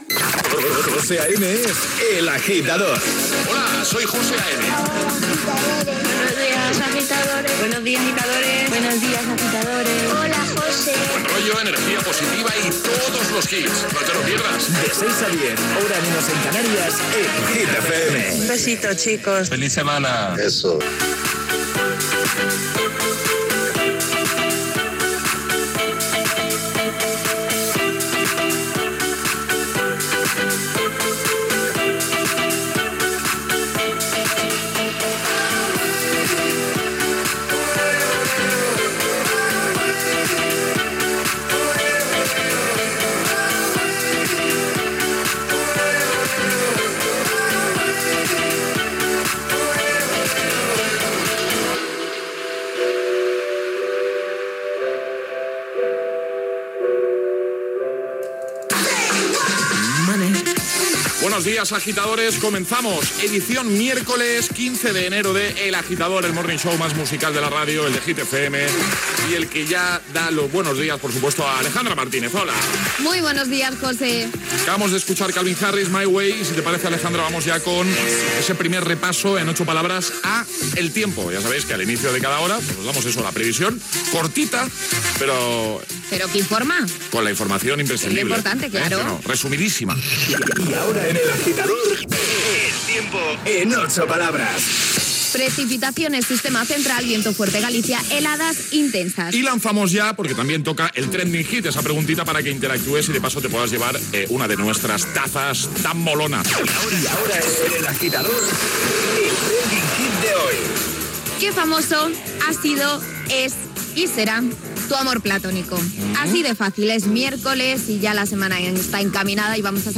Careta del programa, tema musical, data, el temps, "trending hits", indicatiu i tema musical
Entreteniment